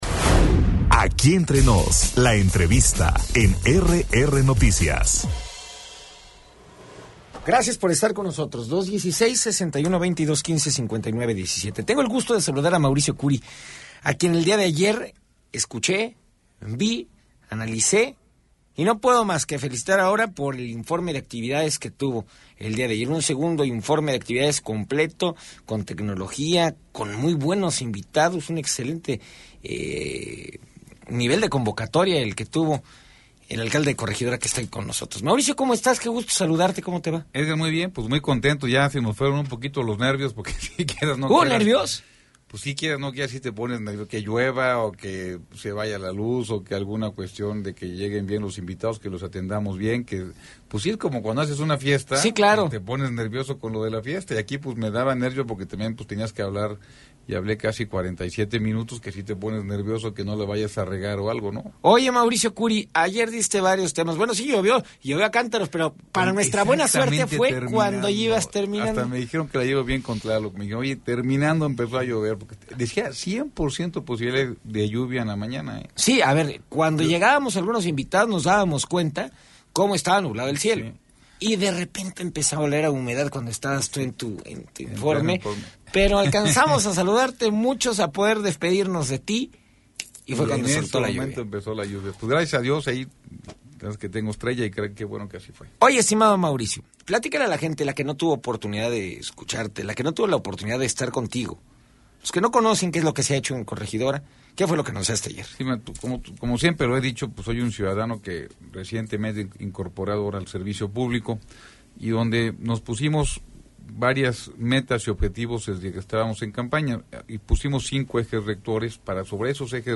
Entrevista con el presidente municipal de Corregidora, Mauricio Kuri González - RR Noticias